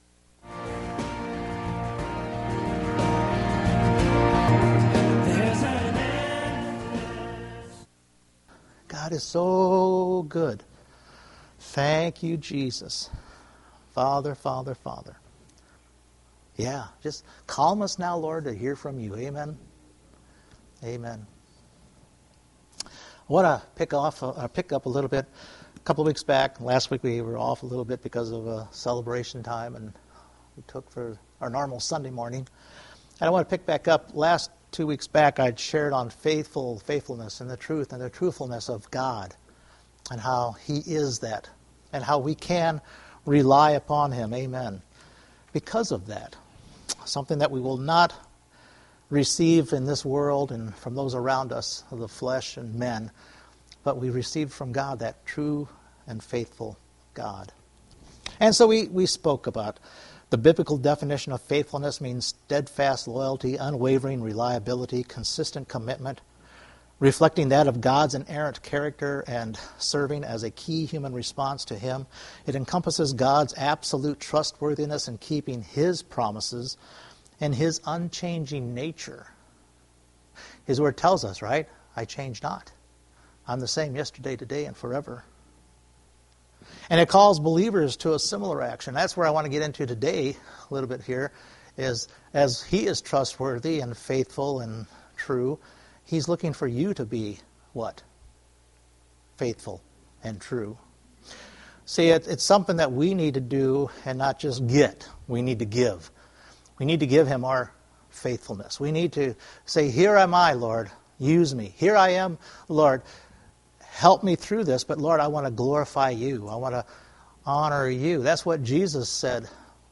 Several Read Service Type: Sunday Morning Just as God is faithful and true for us